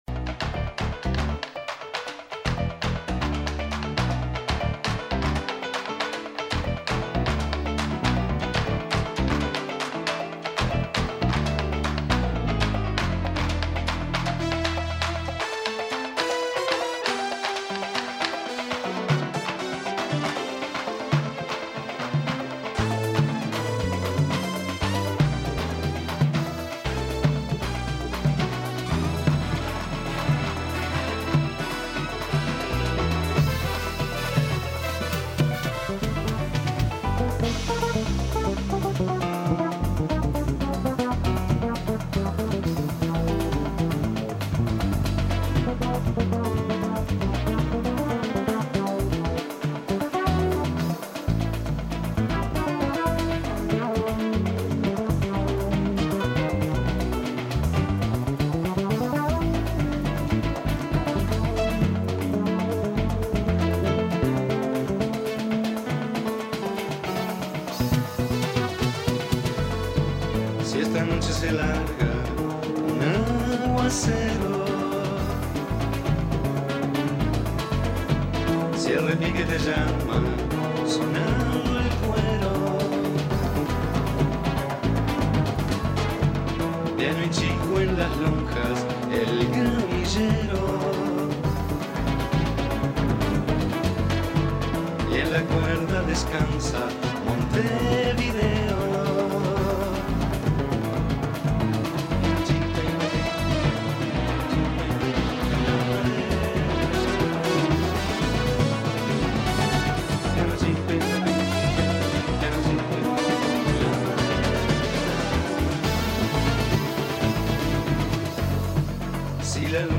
El músico dialogó sobre la grabación de este trabajo. Escuche la entrevista.